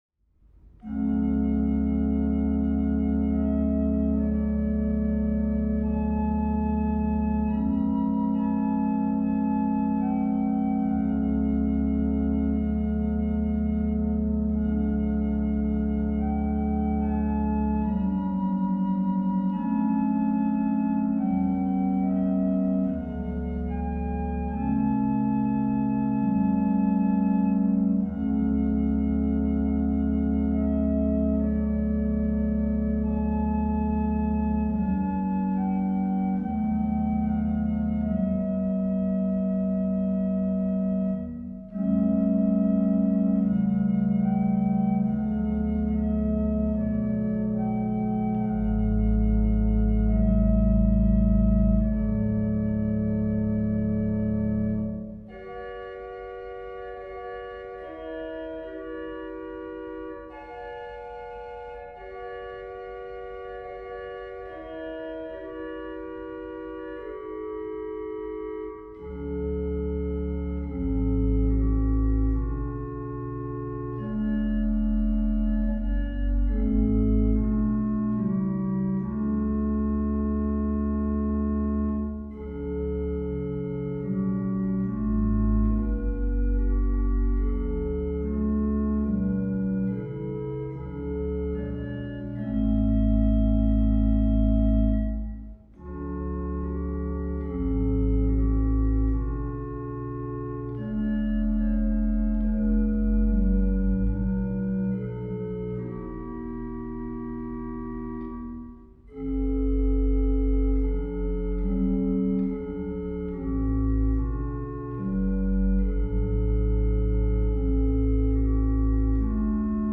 Voicing Organ